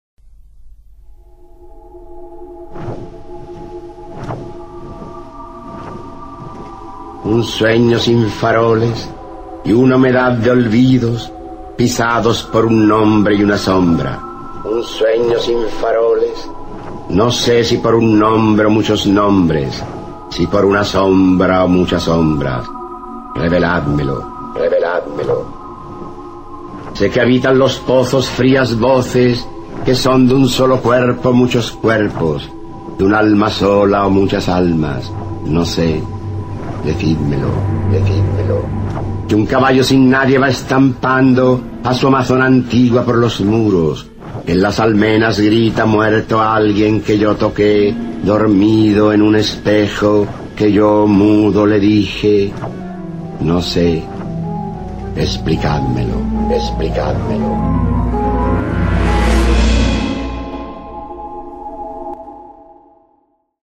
Archivo de sonido con la voz del escritor español Rafael Alberti, quien recita su poema “El ángel del misterio" (Sobre los ángeles, 1927-1928).